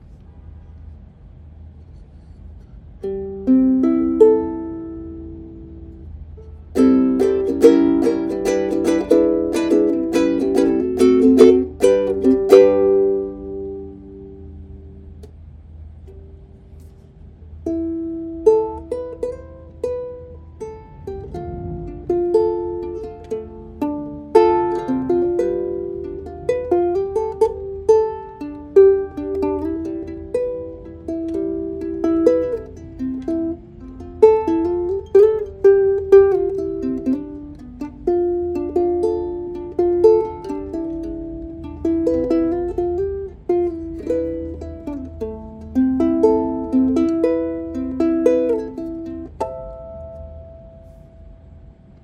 Ukulele
Sound Impression / Comparison Although a low G string is unlikely to produce volume that’s in line with other strings on the HF-1 (due do its soprano size), the Aquila sounds relatively balanced when used with Fremont Black Line Mediums. Compared to the unwound low G string that comes with the Fremont Black Line low G set, it sounds louder and more resonant. Compared to the Fremont Soloist (wound), it feels a less resonant, but maybe a bit brighter. Overall, despite the limitations of a low G on a soprano, it produces a pleasant sound.
HF1_Fremont_Aquila_Unwound_LowG.mp3